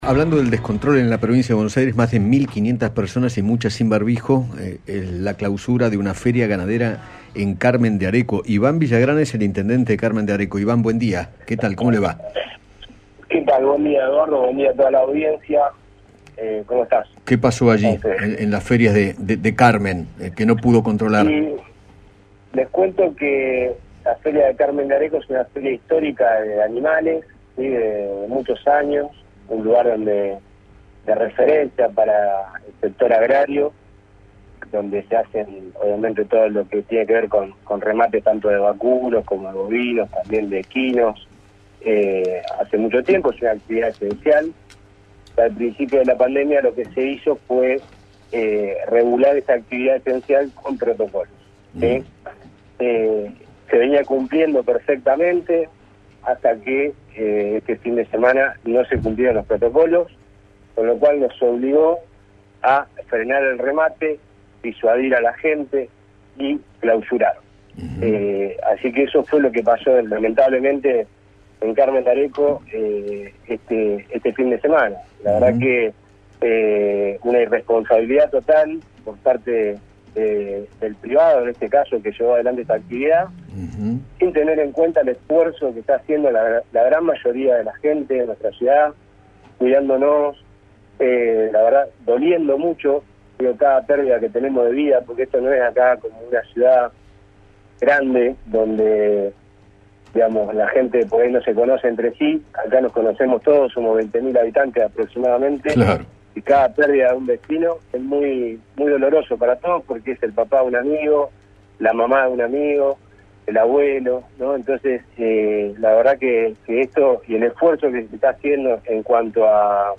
Iván Villagrán, intendente de Carmen de Areco,  habló con Eduardo Feinmann sobre el evento granadero que se realizó este fin de semana y  que debió ser clausurado por el incumplimiento de los protocolos.